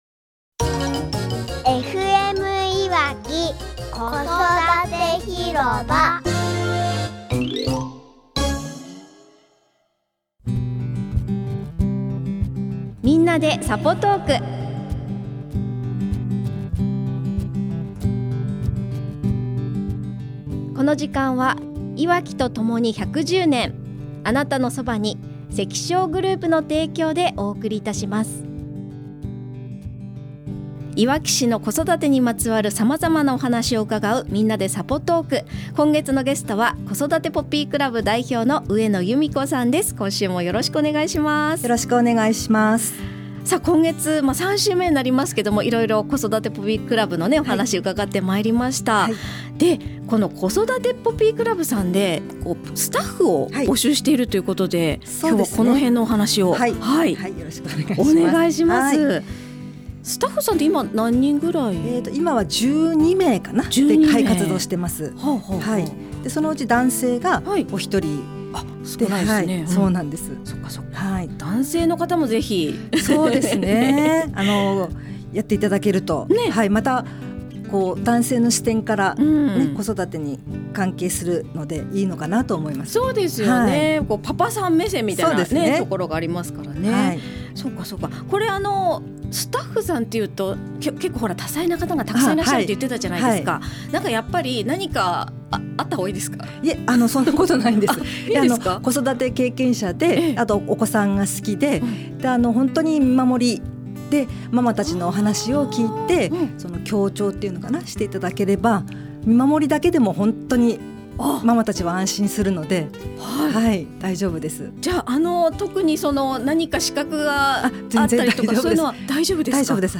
【3月のゲスト】